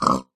Sound / Minecraft / mob / pig / say3.ogg